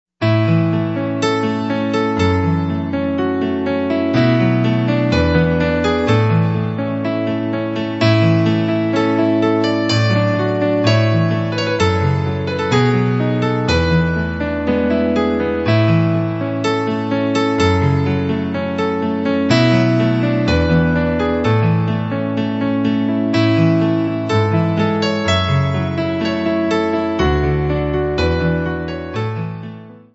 WagnerPiano.mp3